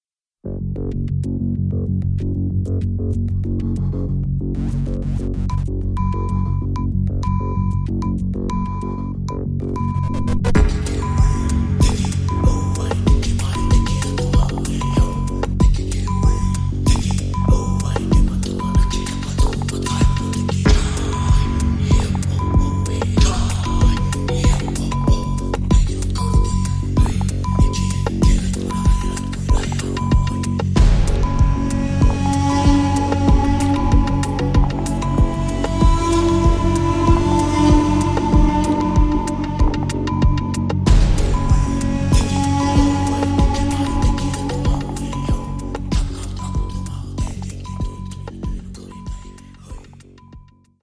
is nice and atmospheric